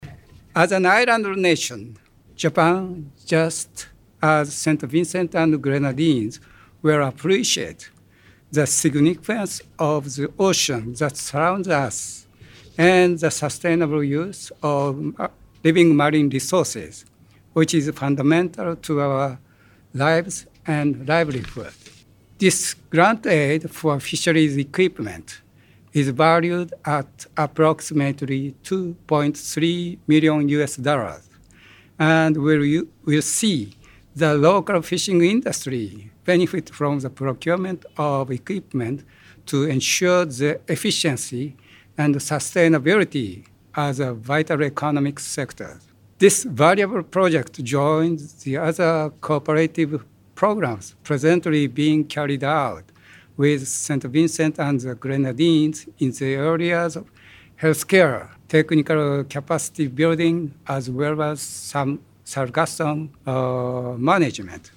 The signing ceremony was held yesterday at the Fisheries Conference room in Kingstown.